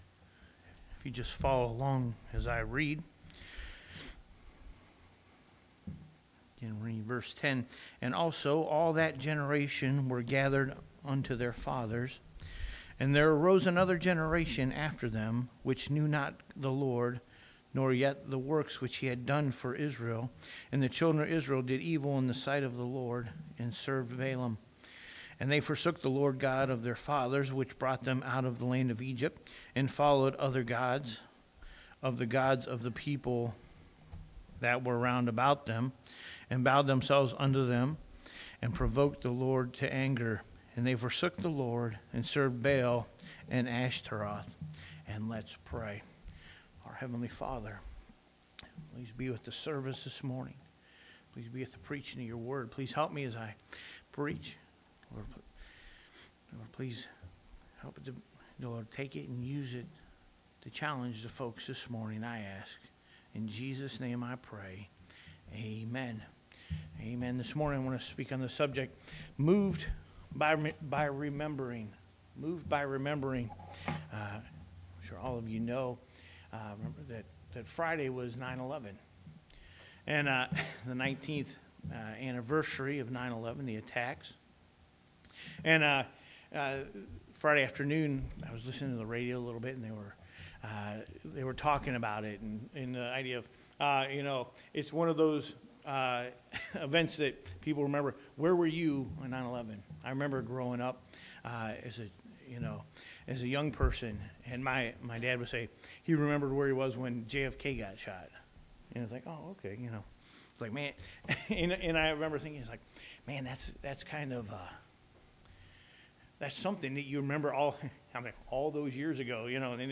rememberance service